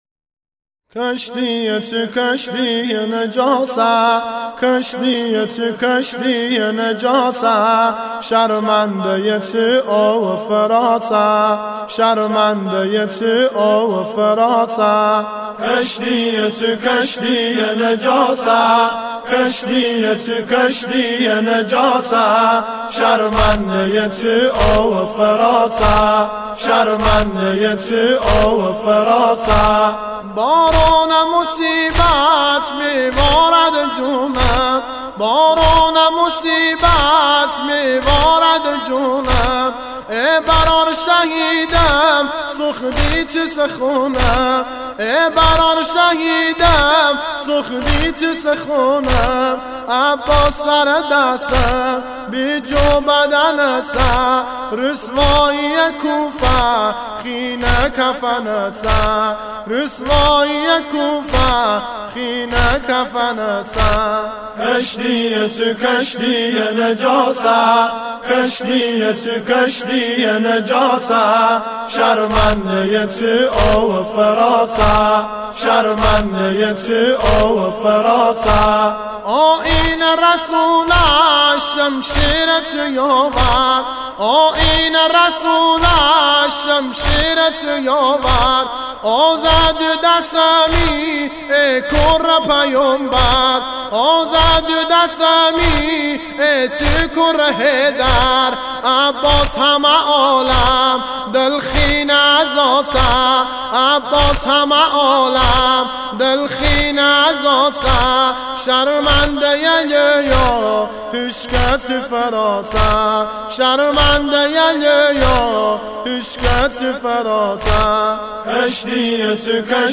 مداحی و نوحه لری